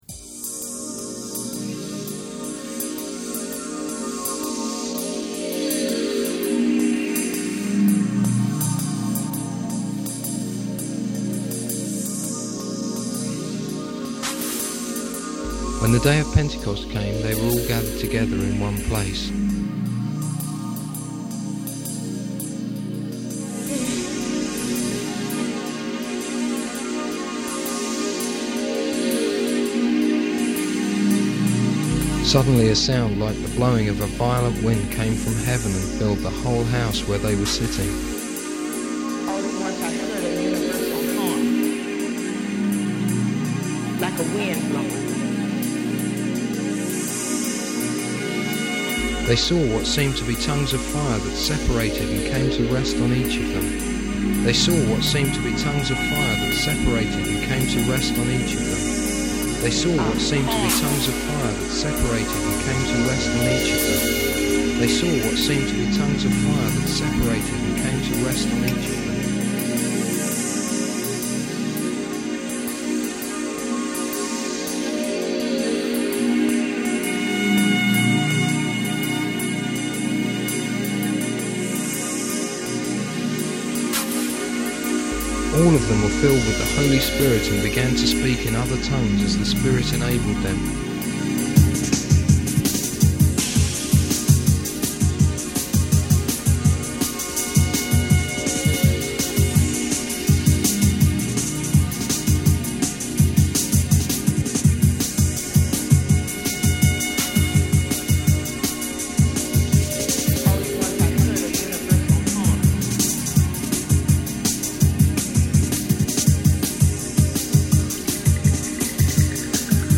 roland samplerit’s very simple – a reading of acts 2 over a music track. the track is universal horn remix 96 by jmj and richie from the album freezone 3 – hope they don’t mind me using it but it is a few years old now anyway. to do the reading i sampled my voice on an old roland js30 sampler. i then assigned the samples to different pads (you can tell this was a few years ago) and as the track played triggered the samples live. that was how i got the mix of voices at the end of the track. anyway i dug out a cassette player and worked out how to turn a track into an mp3 and have uploaded it for you to listen to. it is about 6mb and decidedly low fi in quality but it has a certain something still. see what you think –